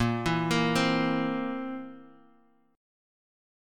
A#sus2b5 chord